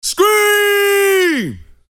S – SCREAM
S-SCREAM.mp3